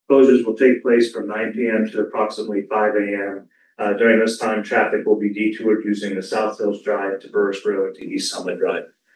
City Manager Ryan Heiland adressed the closure at this week's council meeting.